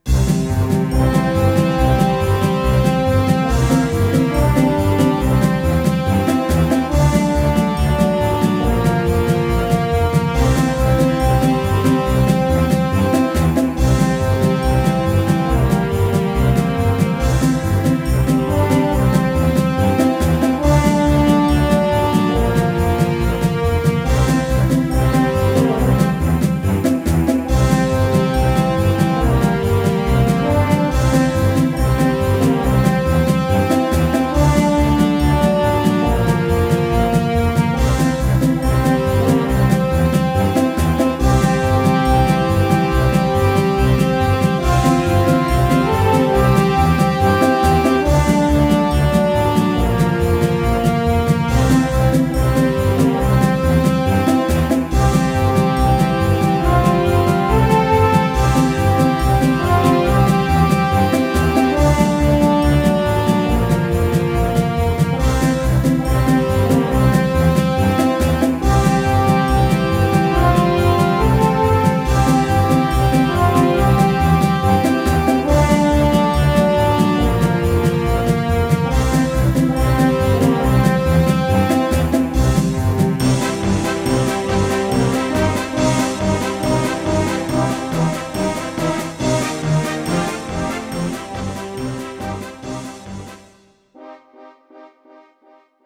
Tempo: 140 bpm / Datum: 06.04.2017